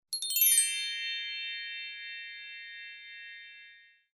На этой странице собраны звуки, создающие ощущение абсолютной чистоты: от мягкого шума убирающегося помещения до переливов хрустальных нот.